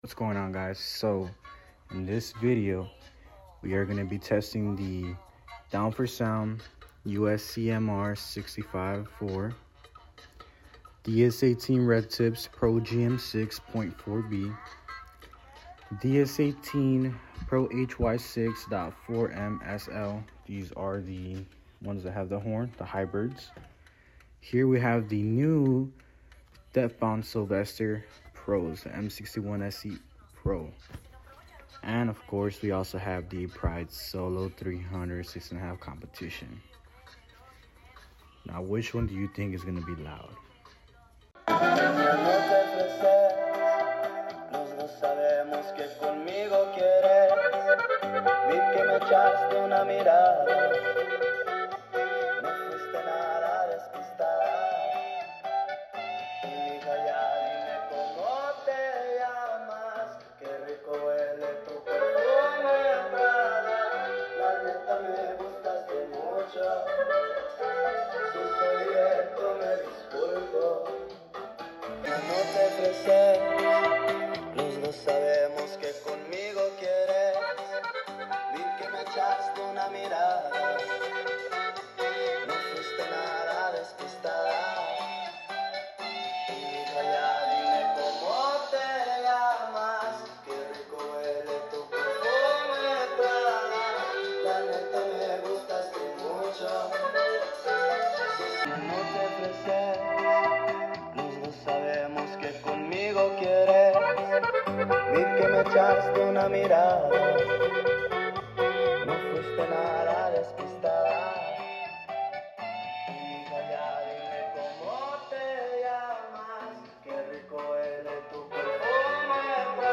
Today we decided to experiment to see which speakers sound the best